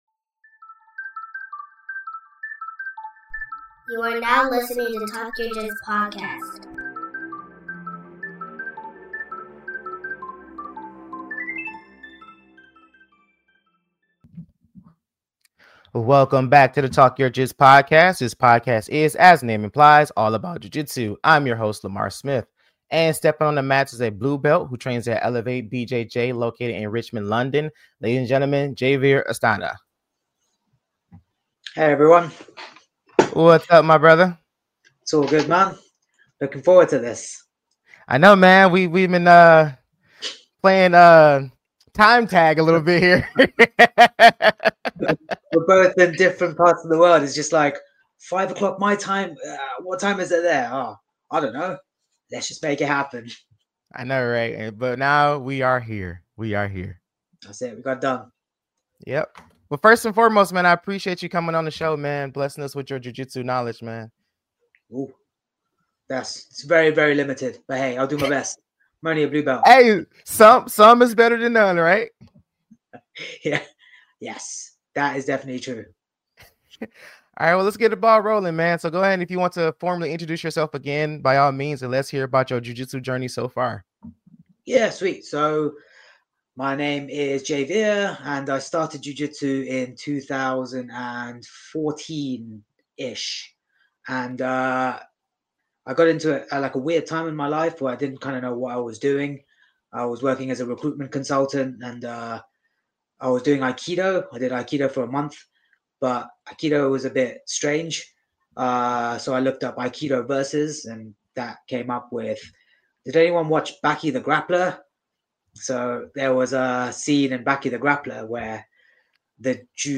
welcome to the talk yo jits podcast! this podcast is as the name implies, all about jiu jitsu journey with me while I sit and talk to other Jiu Jitsu practitioners as we learn how much Jiu Jitsu changed their lives!